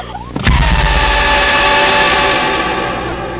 Sound that is used when Michael pops out